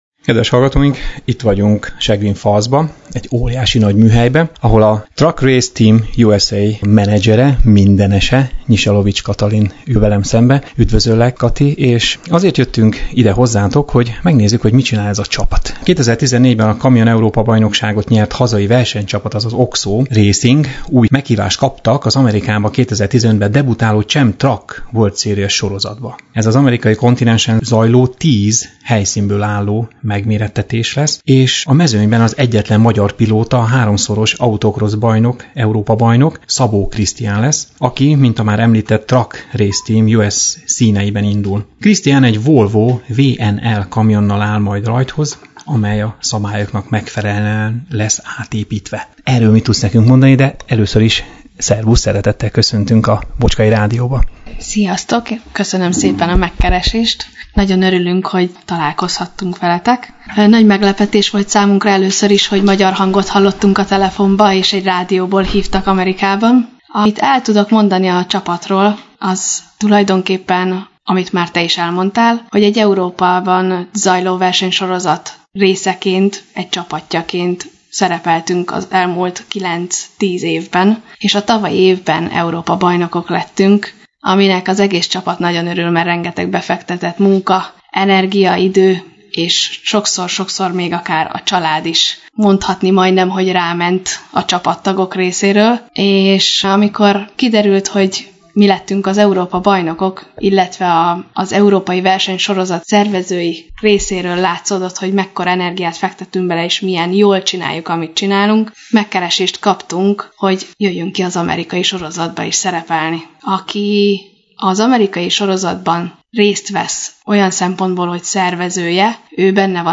Mi, a clevelandi Bocskai Rádió szerkesztői egy gyönyörű napsütéses kora délután meglátogattuk őket a festői környezetben lévő óriás garázsban, Cleveland egyik kertvárosában, Chagrin Fallsban.